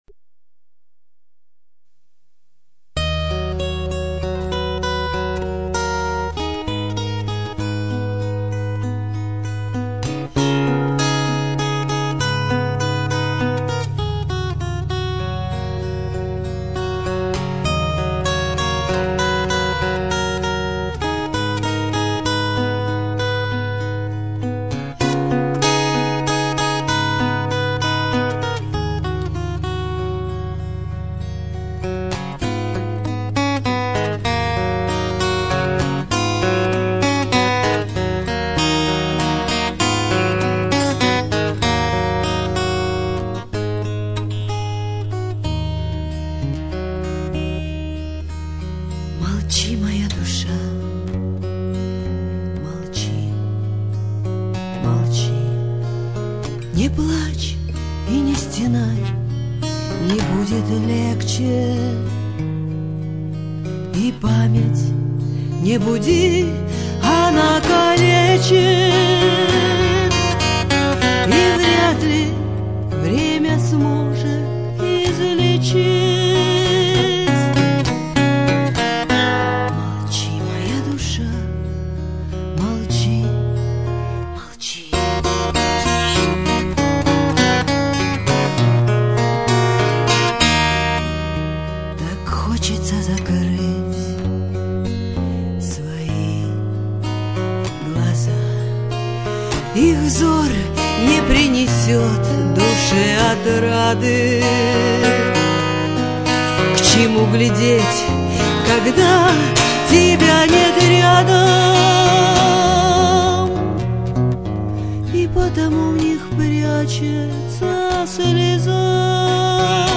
Музыкальное творчество